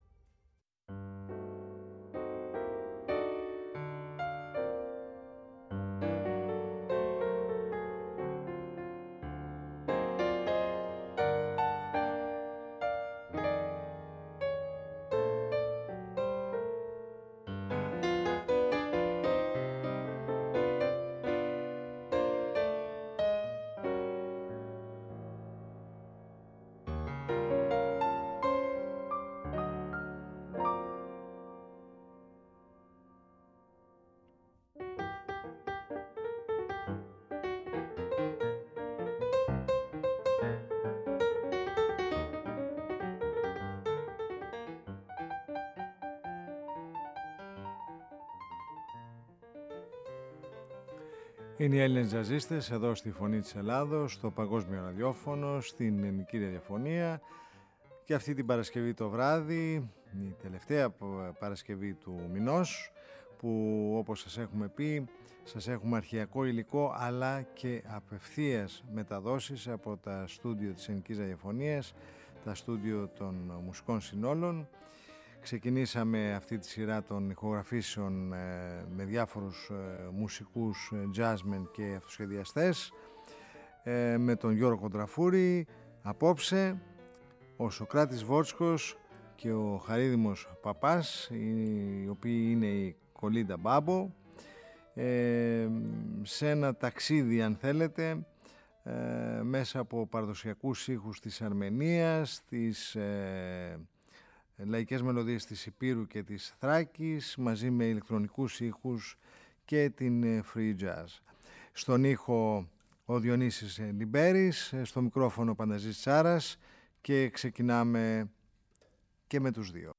πνευστά και ηλεκτρονικοί ήχοι